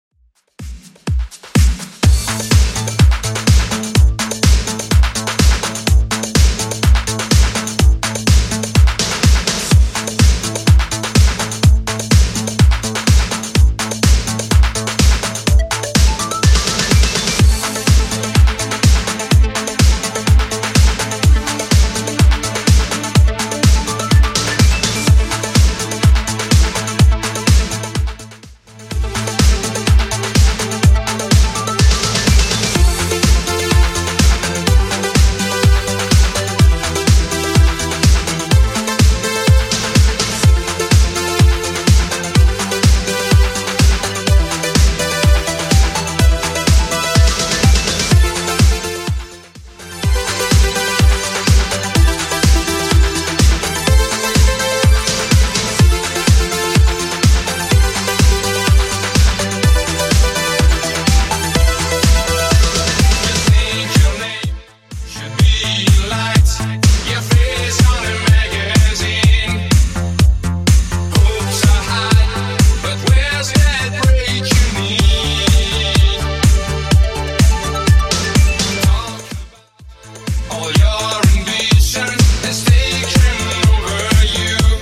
Genre: 2000's
BPM: 130